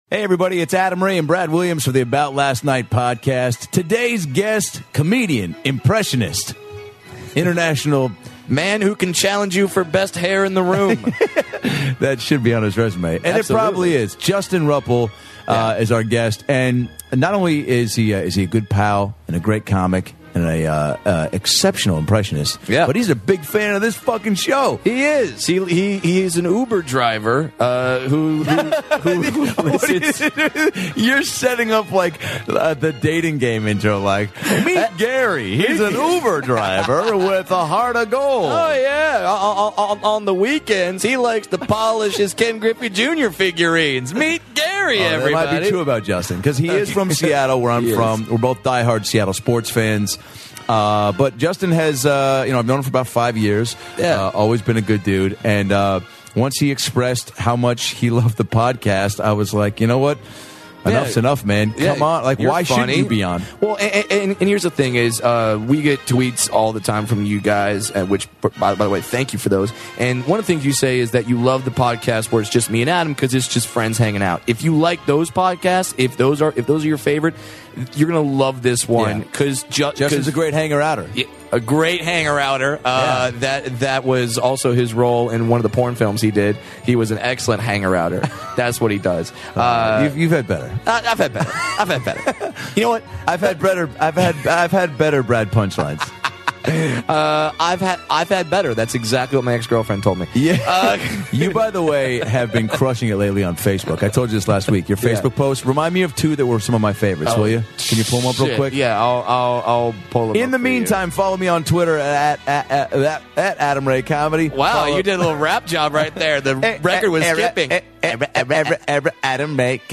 We laughed almost the whole way through.